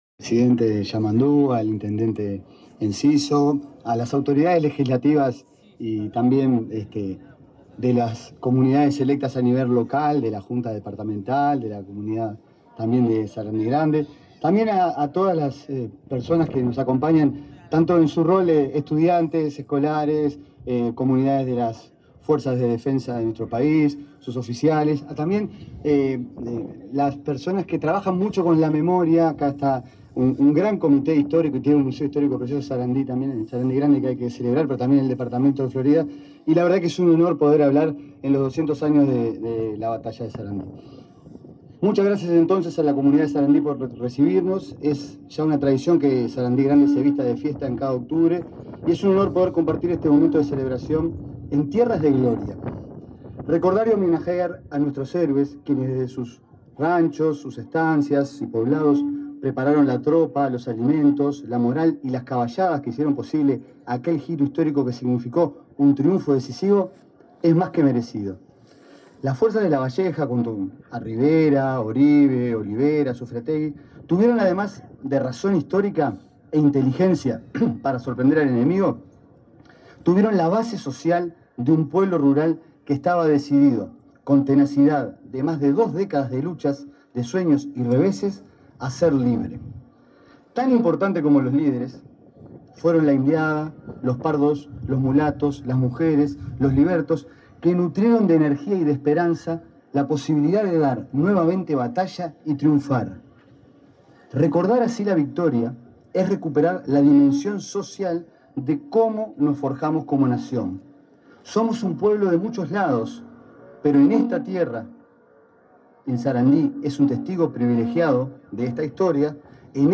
Palabras del director nacional de Educación, Gabriel Quirici
Palabras del director nacional de Educación, Gabriel Quirici 12/10/2025 Compartir Facebook X Copiar enlace WhatsApp LinkedIn El director nacional de Educación, Gabriel Quirici, participó en el acto por el bicentenario de la Batalla de Sarandí, oportunidad en la que valoró la importancia de este enfrentamiento en la historia nacional.